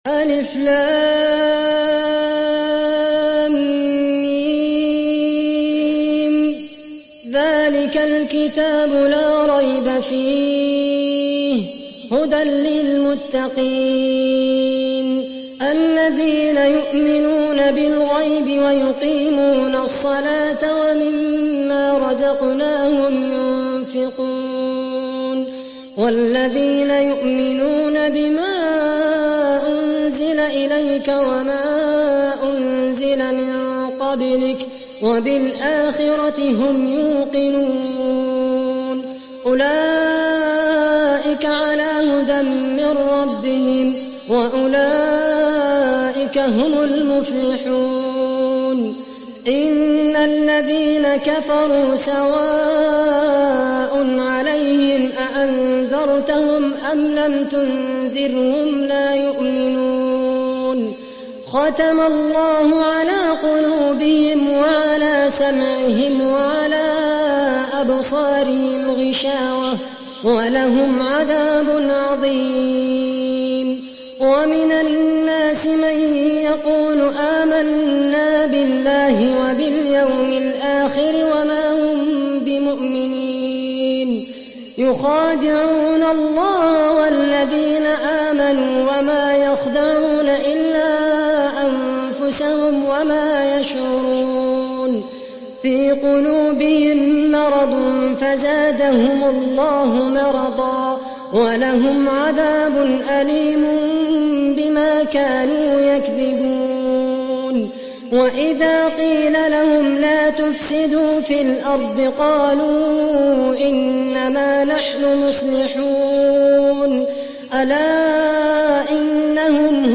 تحميل سورة البقرة mp3 بصوت خالد القحطاني برواية حفص عن عاصم, تحميل استماع القرآن الكريم على الجوال mp3 كاملا بروابط مباشرة وسريعة